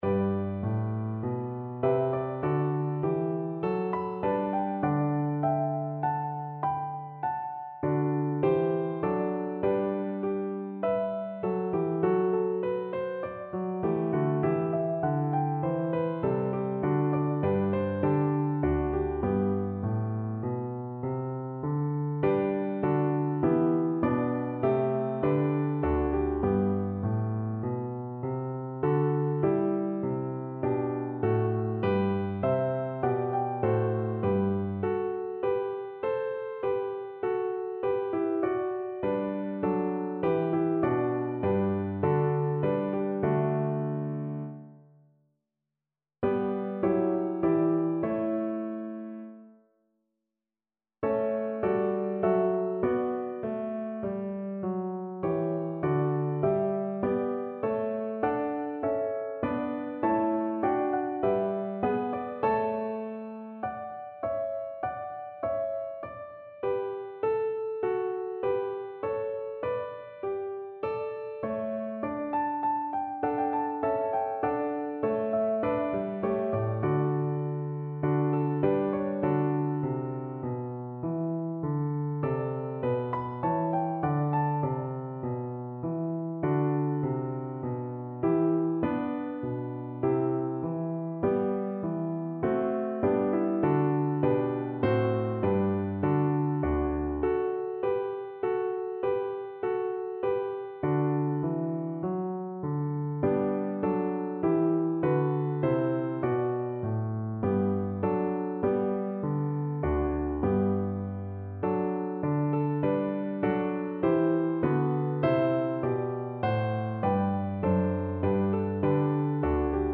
Viola
G major (Sounding Pitch) (View more G major Music for Viola )
4/4 (View more 4/4 Music)
Adagio =50
Classical (View more Classical Viola Music)